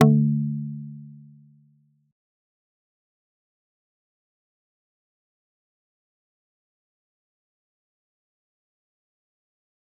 G_Kalimba-D3-pp.wav